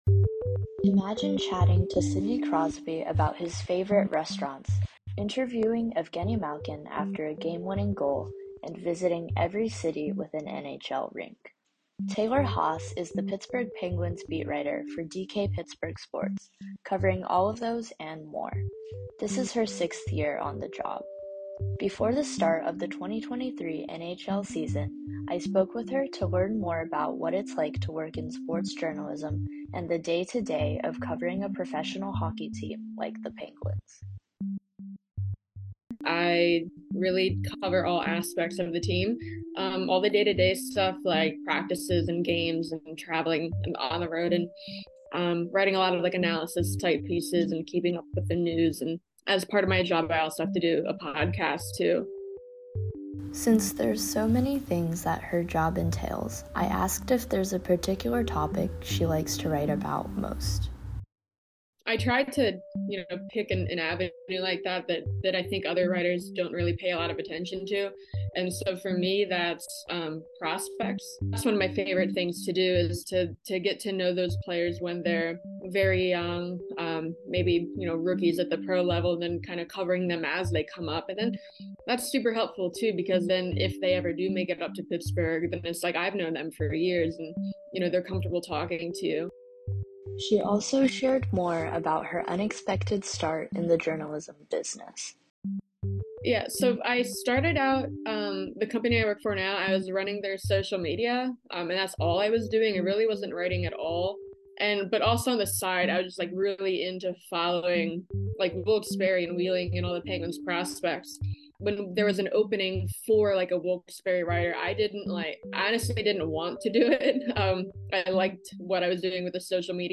We talked about her experience as a hockey reporter, what it's like to be in the sports journalism business, and the season ahead. Listen to a few snippets from our conversation in the podcast below.